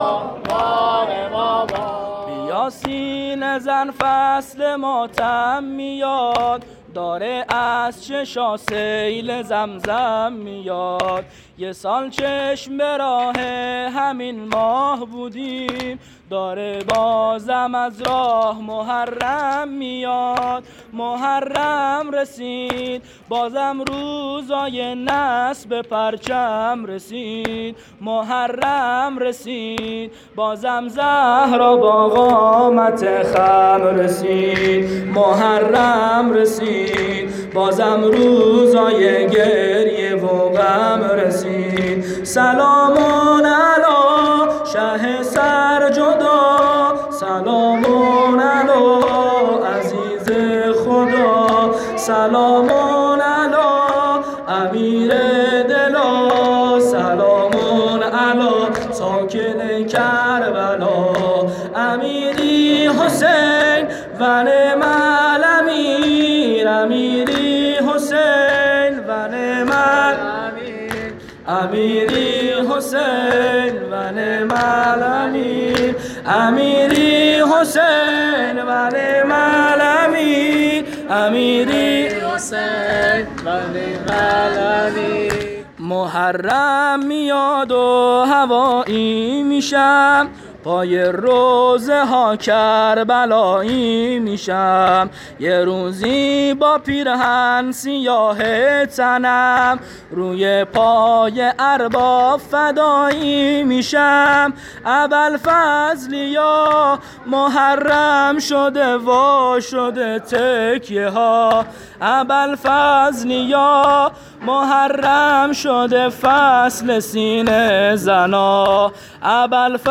شب اول محرم98 هیئت میثاق الحسین (ع) سیستان